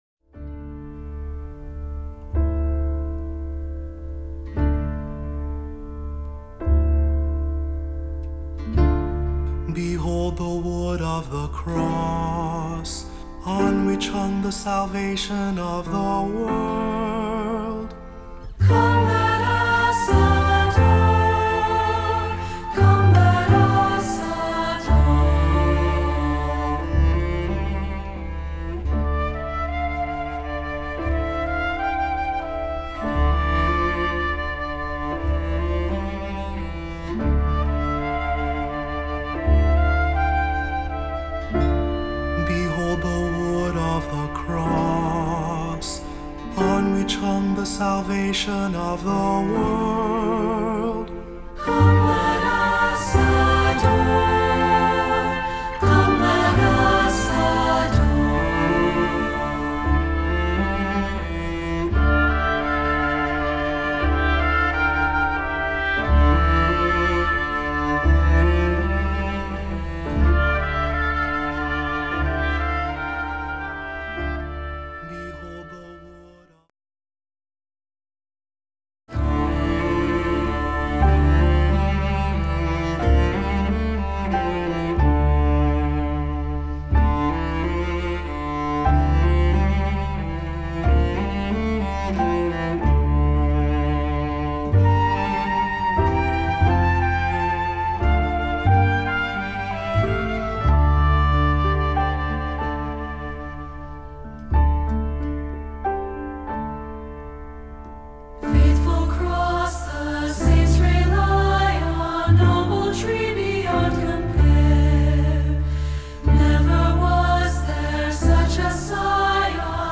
Voicing: Assembly,Cantor,Priest or Presider or Narrator,SAB